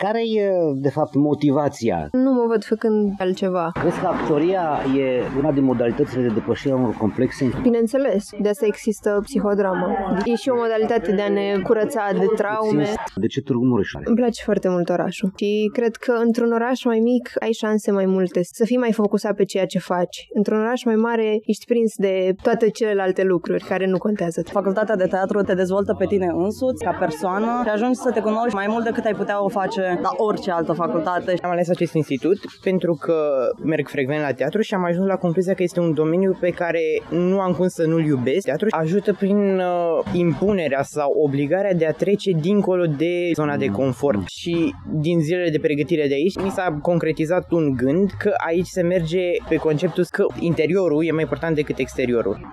La Universitatea de Arte din Târgu Mureș a avut loc azi festivitatea primului clopoțel.
Surprinzător de maturi, bobocii de la actorie cred că fiecare generație își are sacrificiile ei și spun că au ales Tg. Mureșul pentru tradiție și modul profesionist în care dascălii de aici își instruiesc studenții: